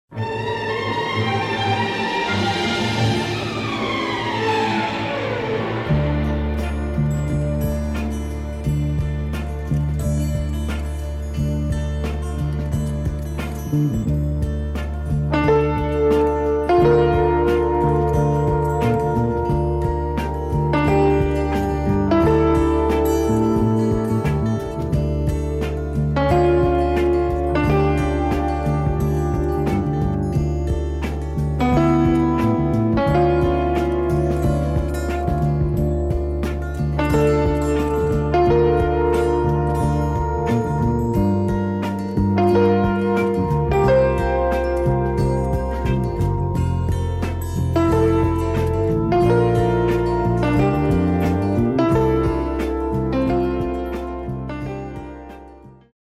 terrific / groovy / cinematic theme
It's ready made hip hop, no ?